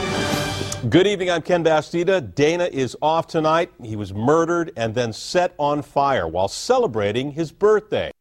Tags: News blooper news news anchor blooper bloopers news fail news fails broadcaster fail